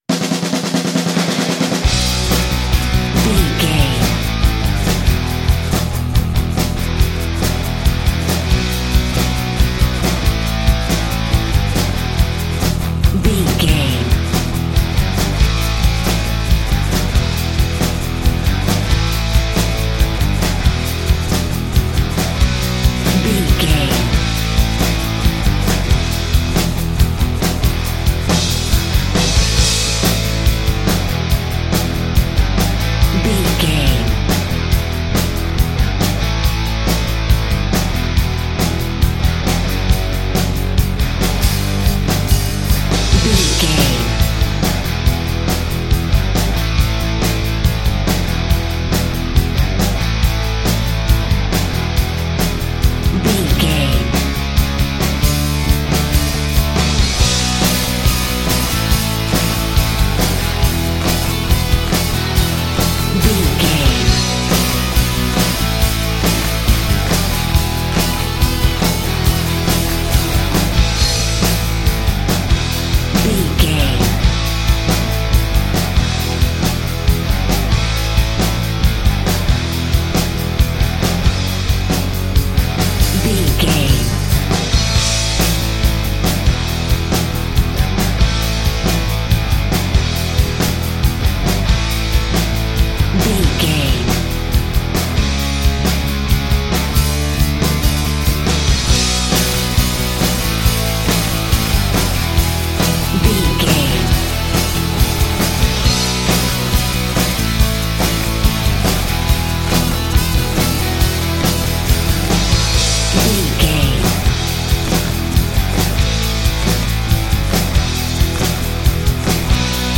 Ionian/Major
drums
electric guitar
Sports Rock
hard rock
metal
lead guitar
bass
aggressive
energetic
intense
nu metal
alternative metal